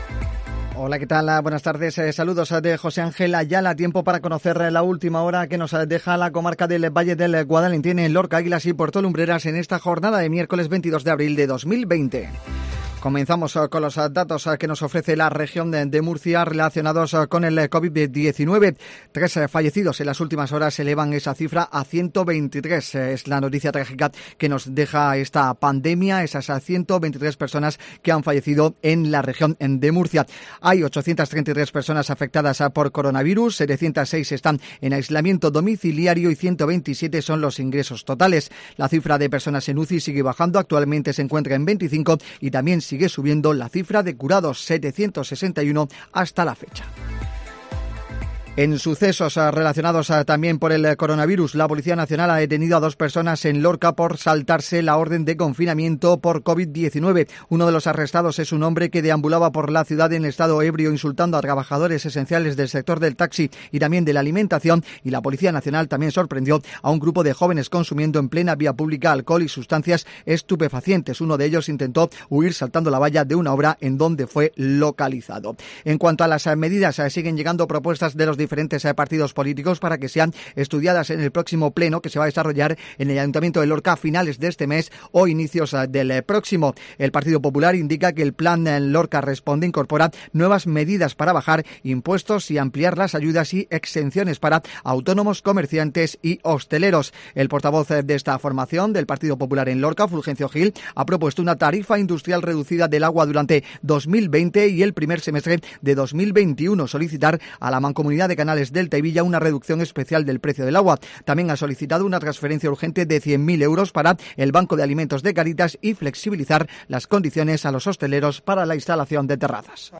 INFORMATIVO MEDIODÍA COPE LORCA